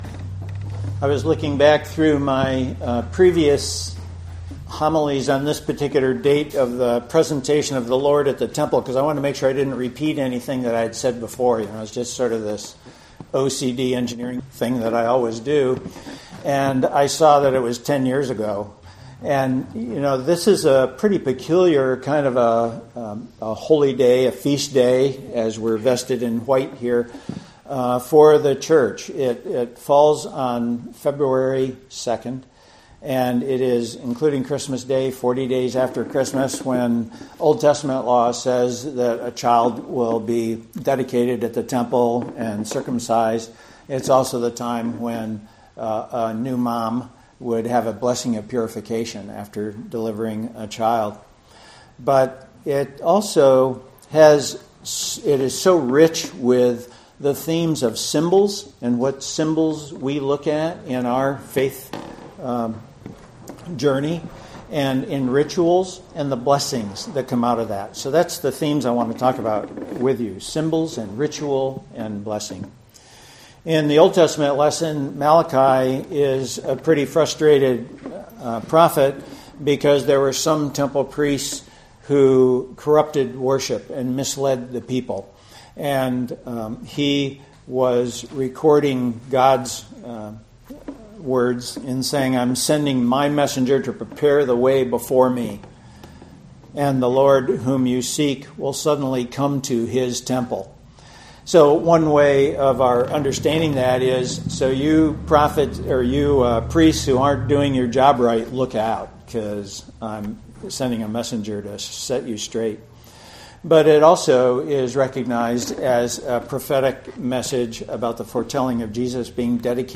Sermon: